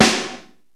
HER SNARE.wav